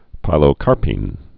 (pīlō-kärpēn)